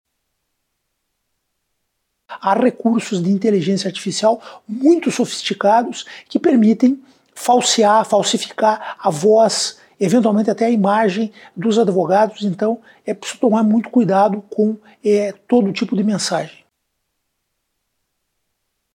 Célio Waldraff também alertou para recursos de inteligência artificial, que podem simular uma voz semelhante à do advogado, para dar credibilidade para a falsa solicitação.